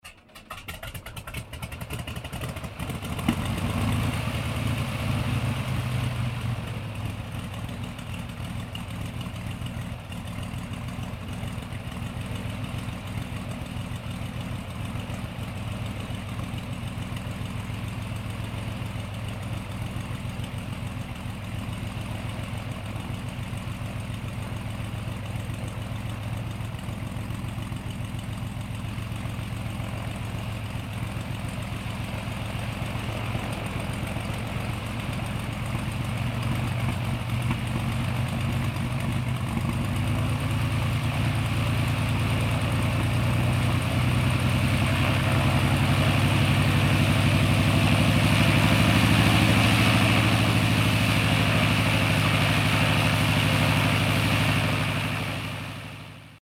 Звуки пропеллера
Запустили пропеллерный самолёт